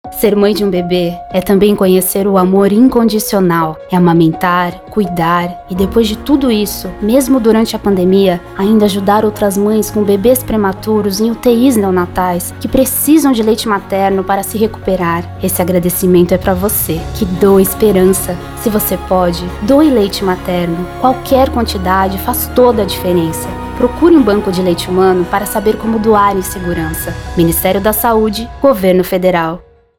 spot-30.mp3